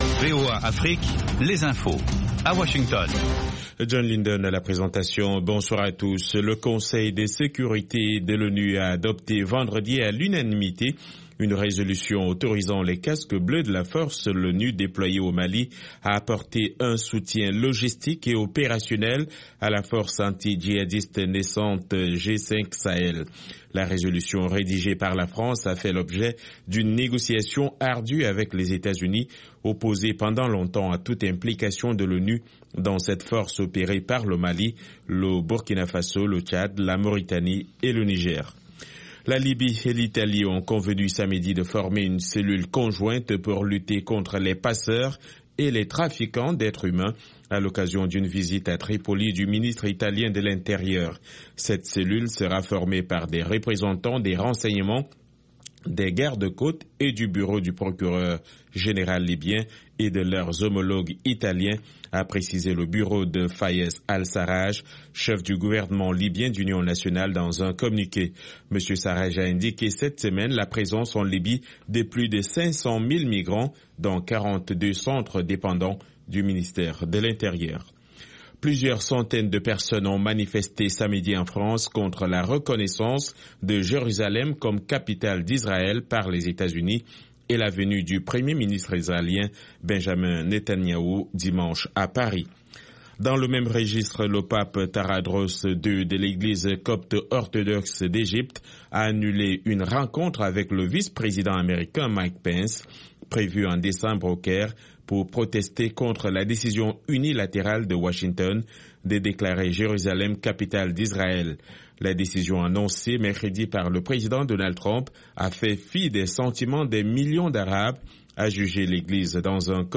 5 Minute Newscast